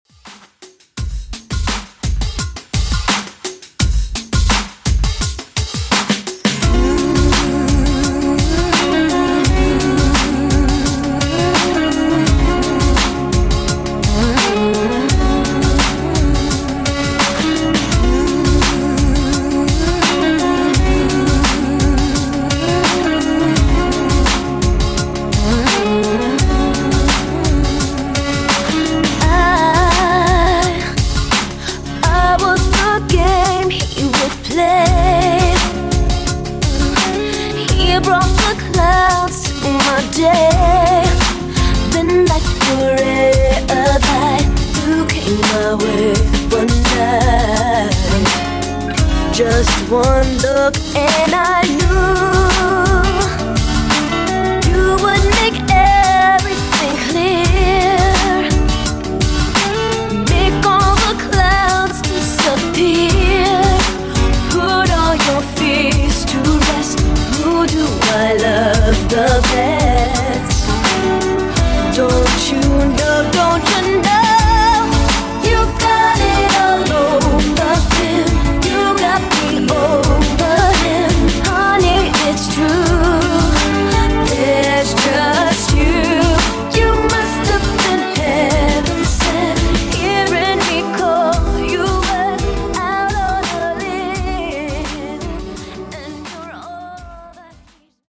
Slow Jam Remix)Date Added